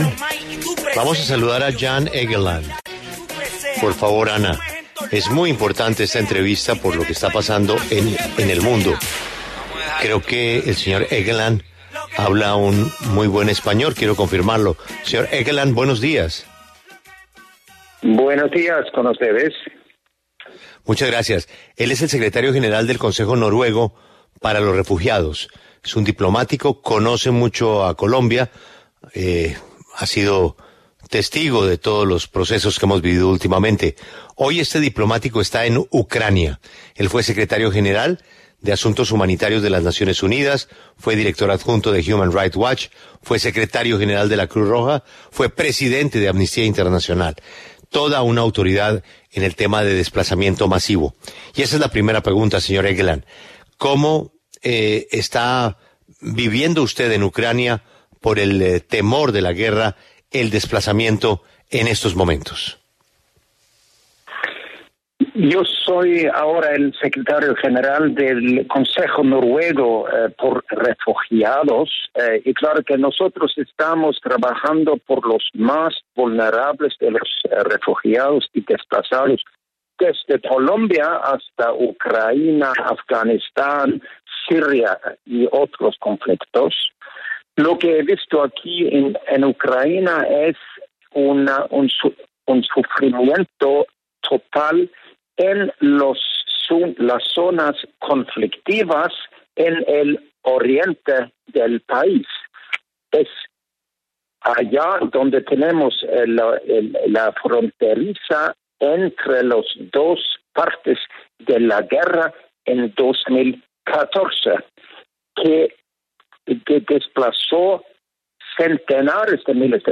Jan Egeland, secretario general del Consejo Noruego para los Refugiados, habló en La W sobre las posibilidades de un desplazamiento de habitantes de Ucrania ante la crisis con Rusia.